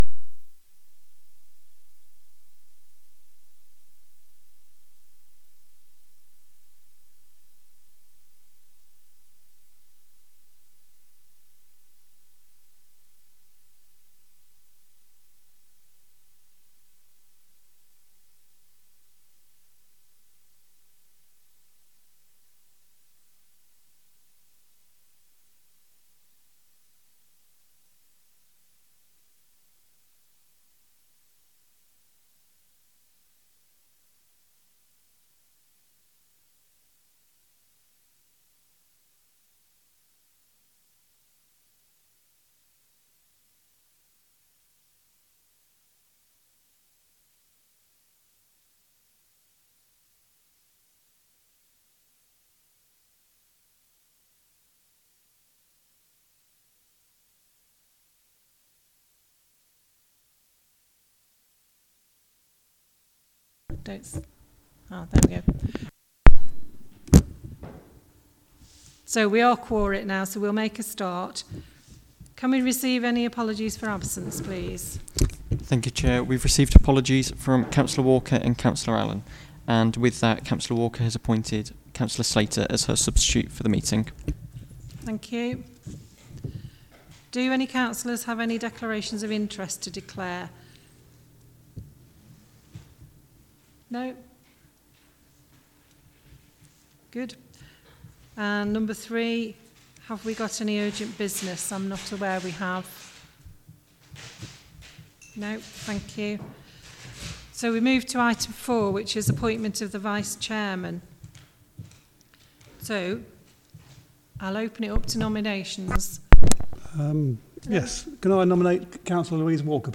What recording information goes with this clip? Coltman VC Room